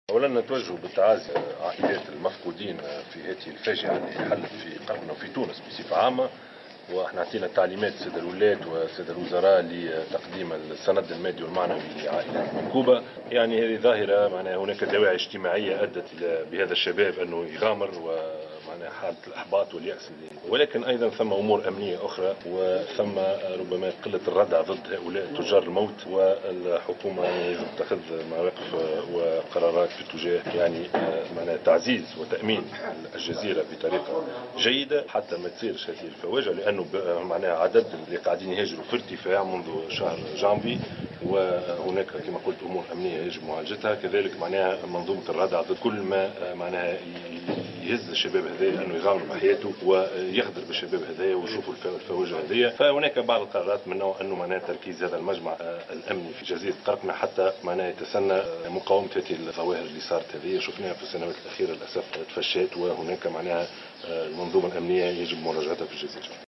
قال رئيس الحكومة يوسف الشاهد بعد تحوله إلى جزيرة قرقنة صباح اليوم عقب حادثة غرق مركب "الحراقة" إن الحكومة ستتخذ اجراءات ردعية ضد تجار الموت الذين يقومون بتنظيم رحلات "الحرقة".